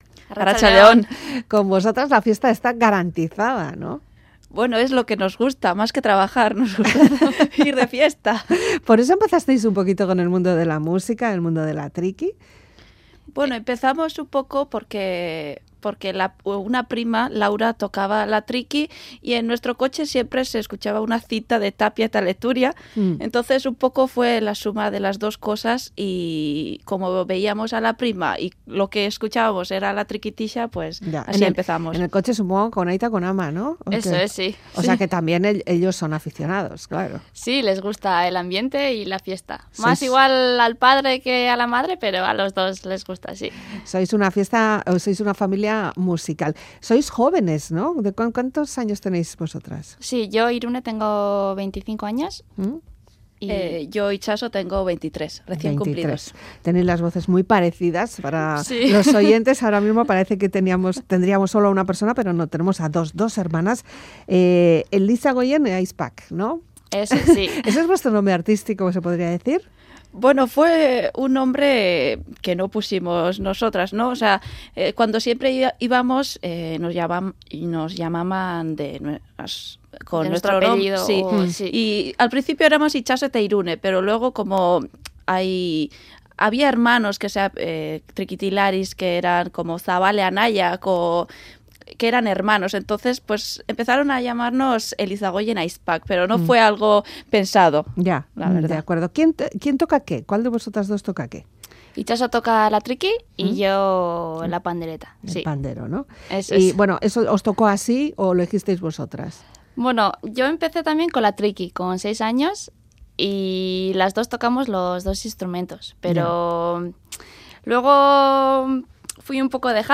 Trikitixa energética